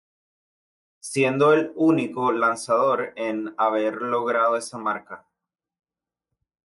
Hyphenated as lo‧gra‧do Pronounced as (IPA) /loˈɡɾado/